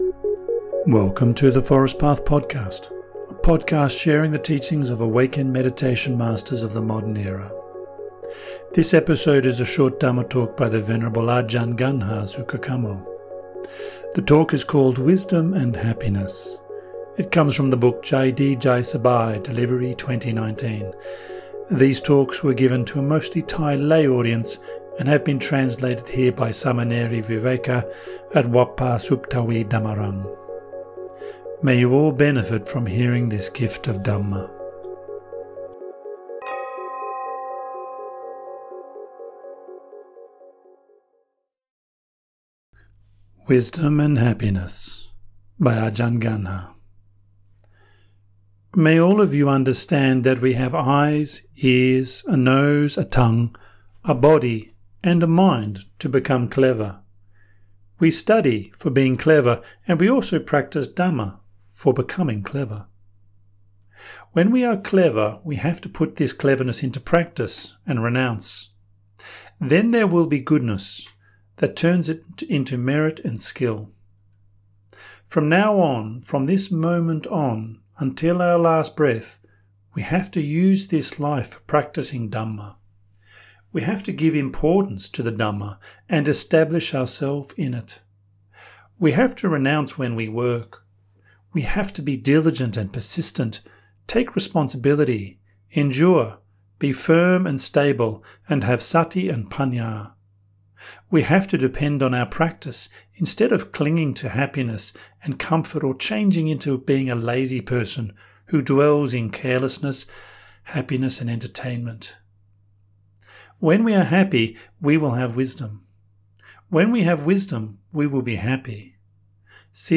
Narrated translations of meditation masters of the forest tradition of Theravada Buddhism.